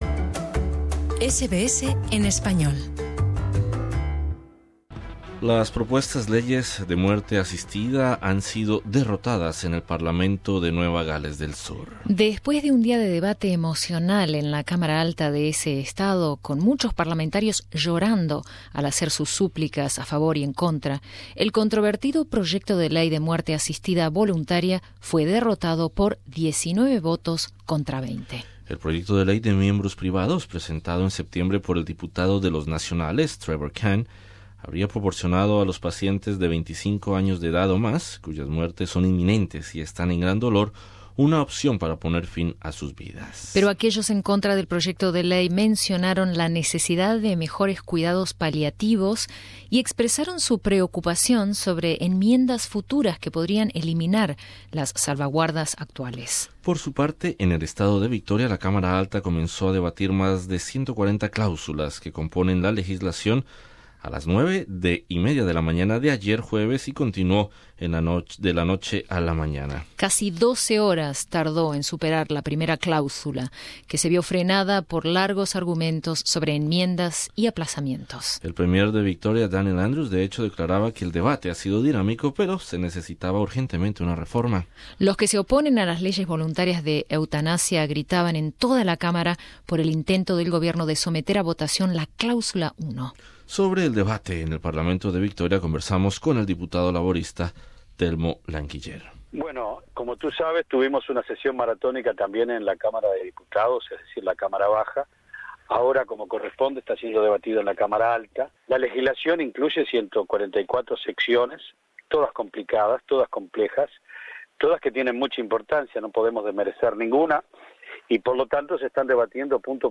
Sobre el debate en el parlamento de Victoria, conversamos con el diputado laborista, Telmo Laguiller.